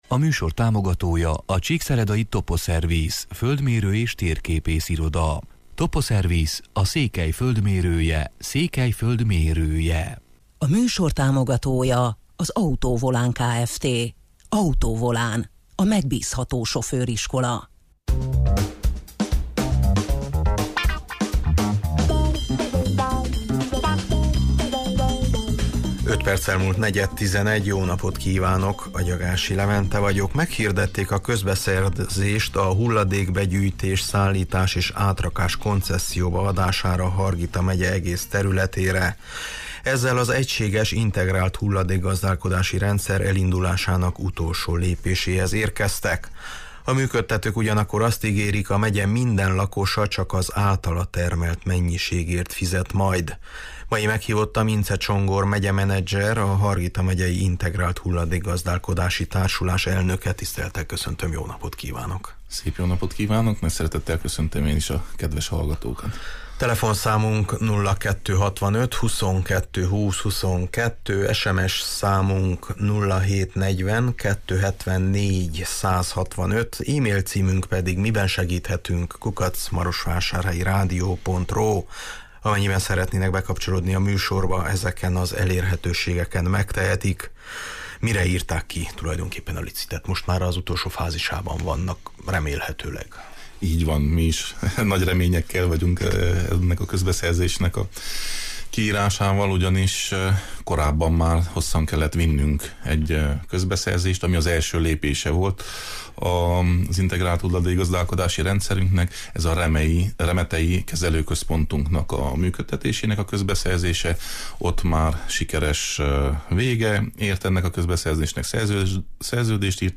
Mai meghívottam Incze Csongor megyemenedzser, a Hargita megyei Integrált Hulladékgazdálkodási Társulás elnöke: